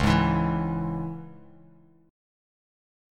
C#m Chord
Listen to C#m strummed